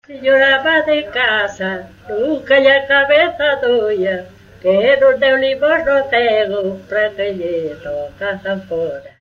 Coplas
Tipo de rexistro: Musical
Lugar de compilación: Lugo - Lugo (San Pedro)
Soporte orixinal: Casete
Instrumentación: Voz
Instrumentos: Voz feminina